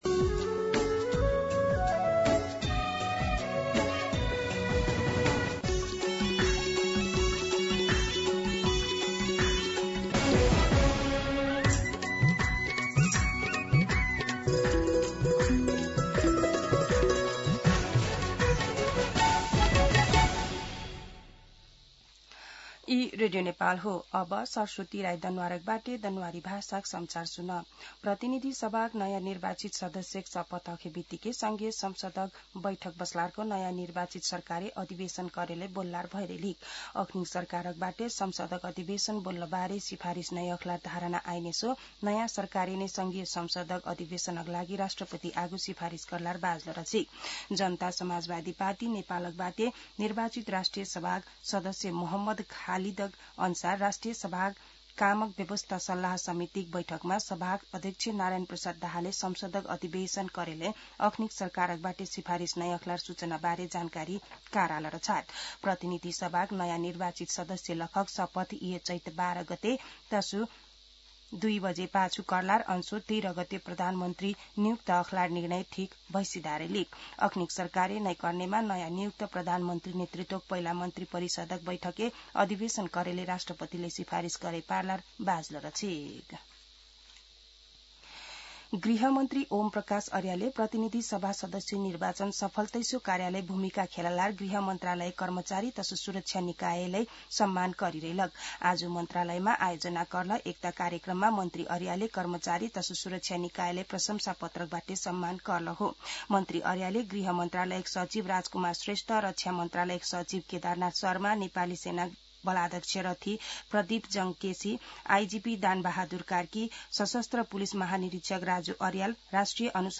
दनुवार भाषामा समाचार : ९ चैत , २०८२
Danuwar-News-09.mp3